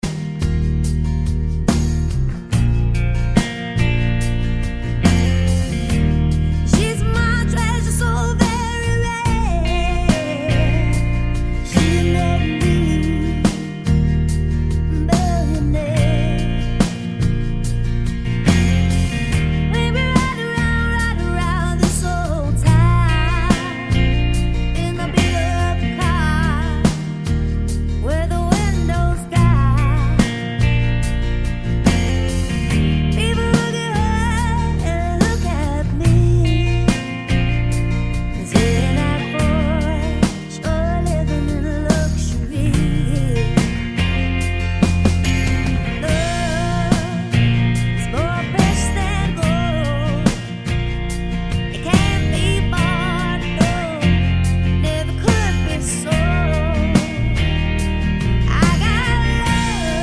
no Backing Vocals Country (Male) 3:25 Buy £1.50